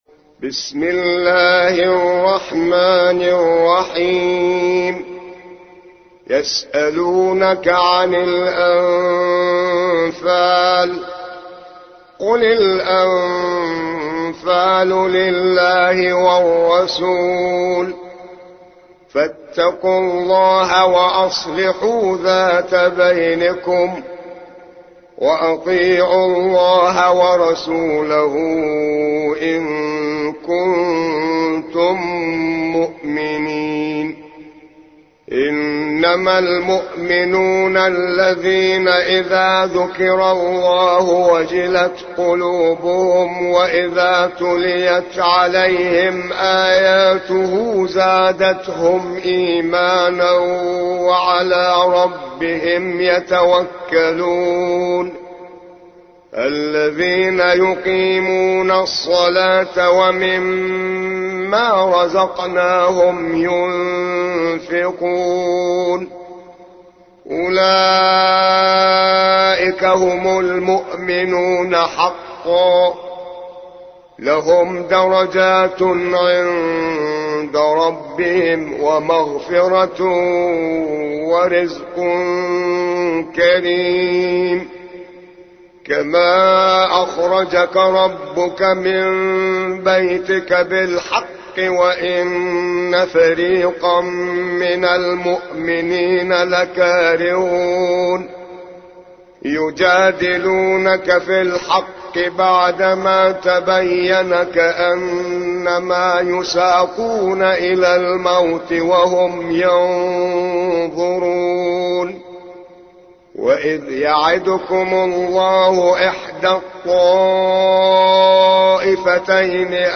8. سورة الأنفال / القارئ